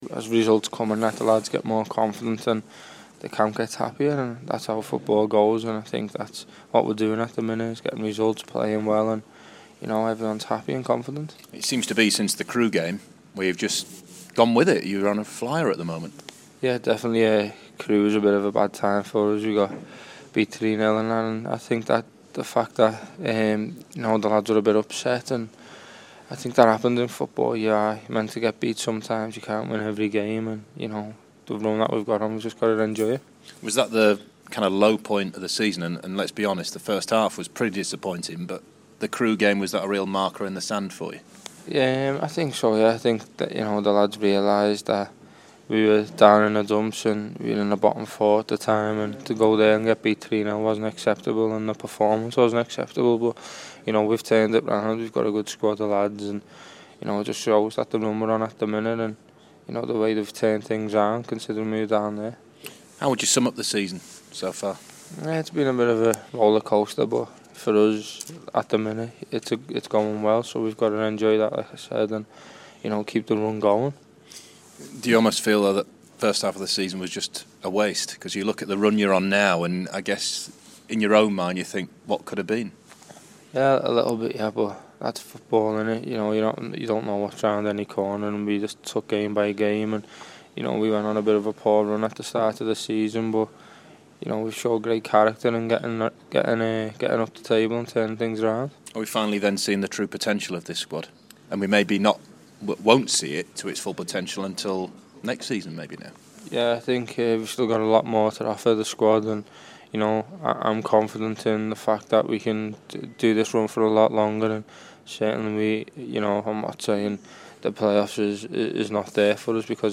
The Blades forward speaking